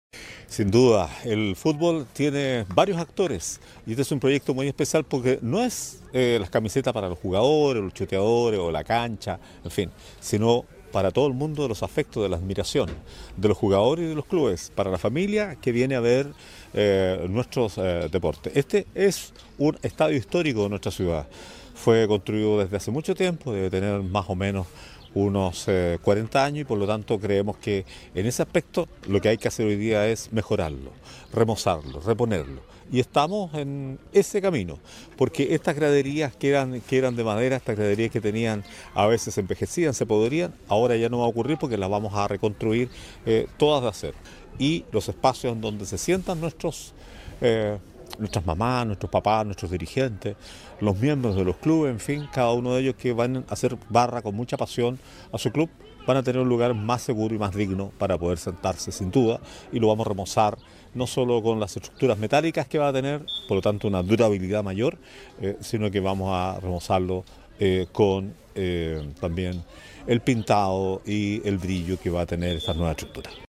Por otra parte, el alcalde Antonio Rivas destacó la realización de los actos deportivos como un punto de encuentro familiar de gran relevancia social.